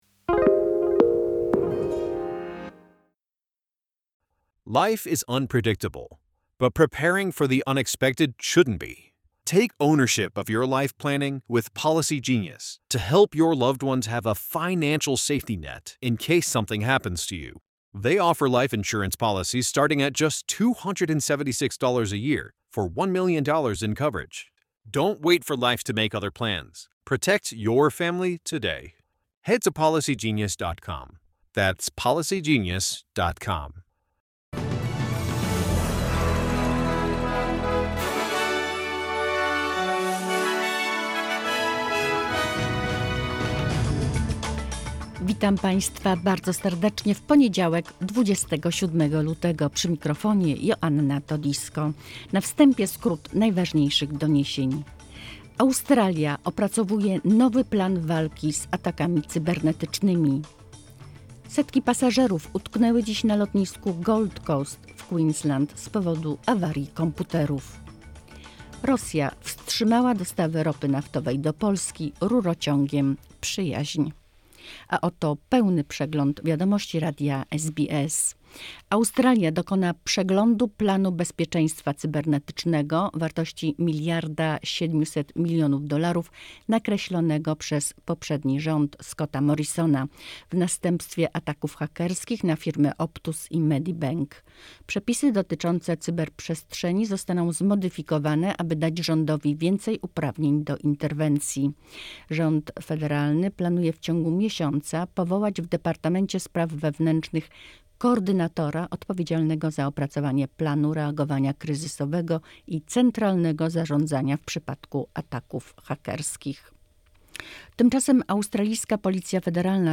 polish news bulletin Source: SBS